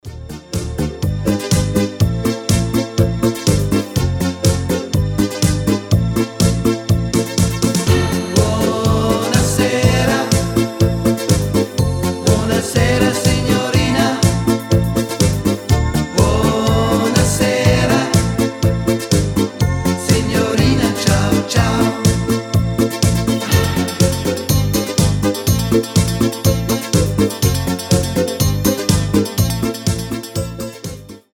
Итало диско Ретро Поп